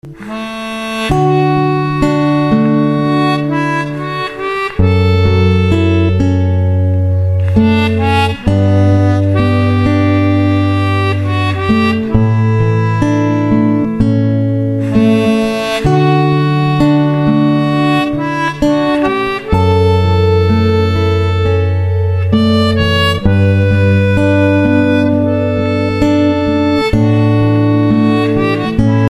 bgm: